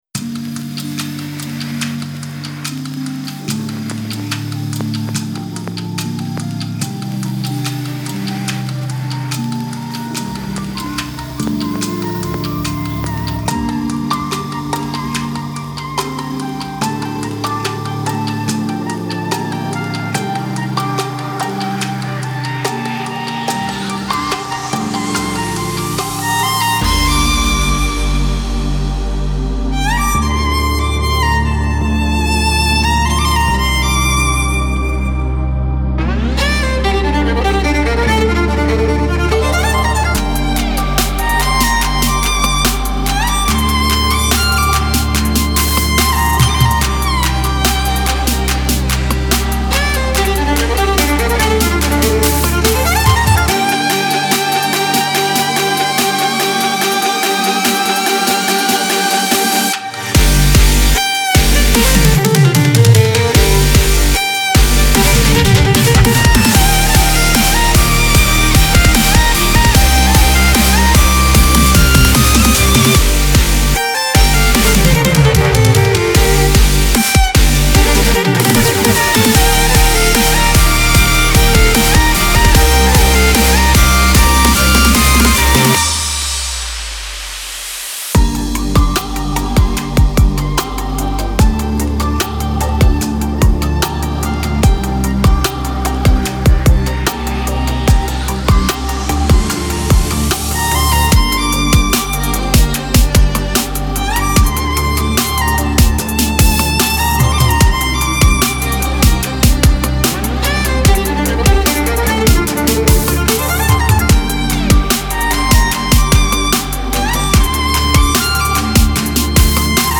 Genre : Classical, Electronic